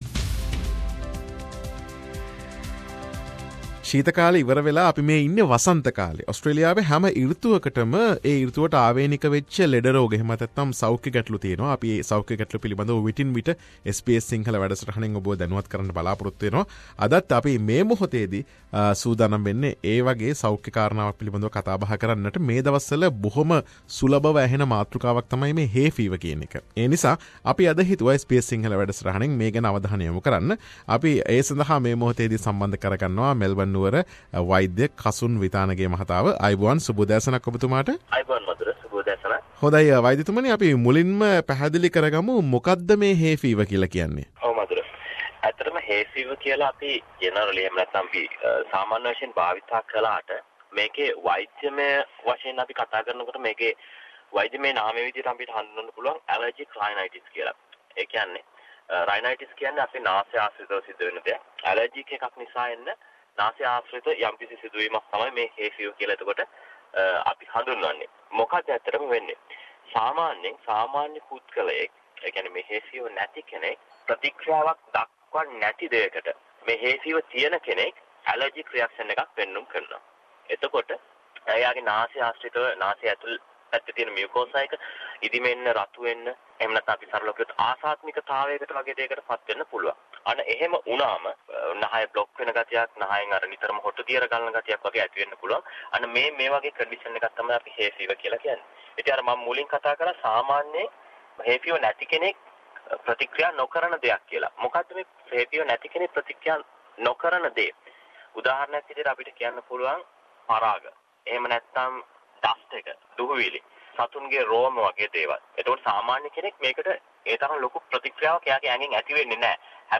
Medical discussion